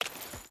Water Chain Walk 3.ogg